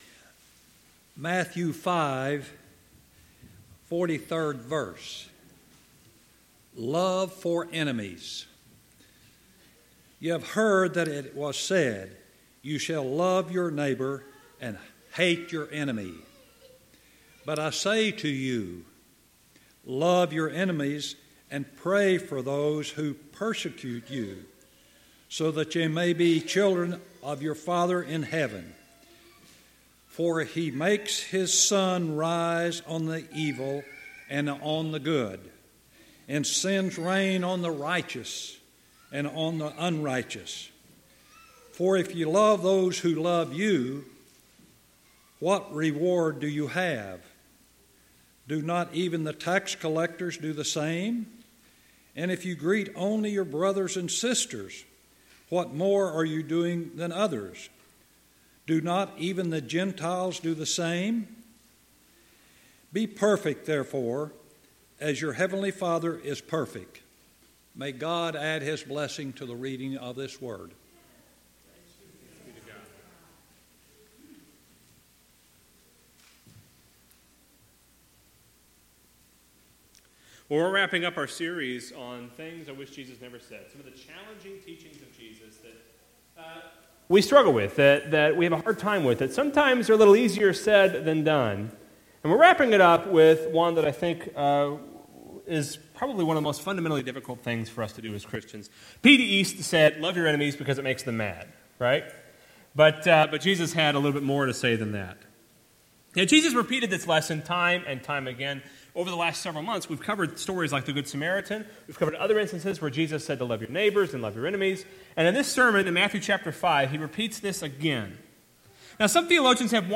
Passage: Matthew 5:43-48 Service Type: Sunday Morning